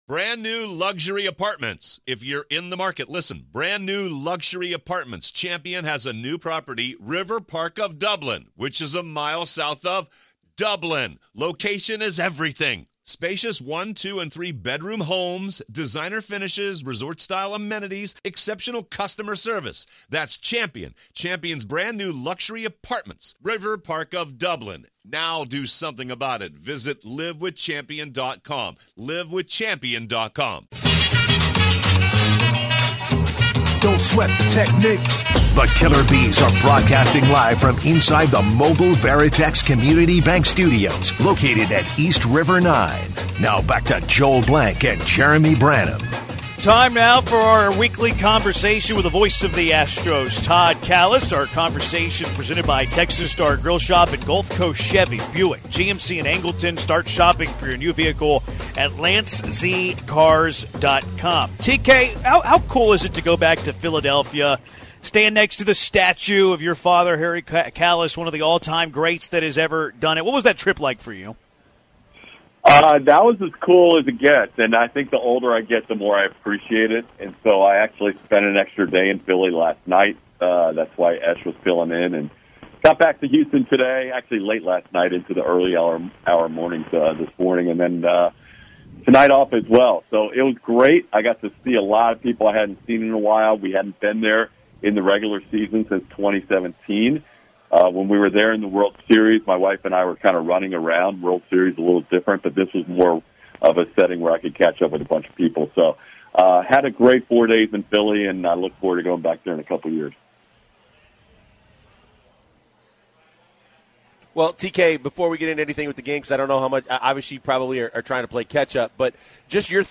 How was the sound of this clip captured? LIVE from East River 9!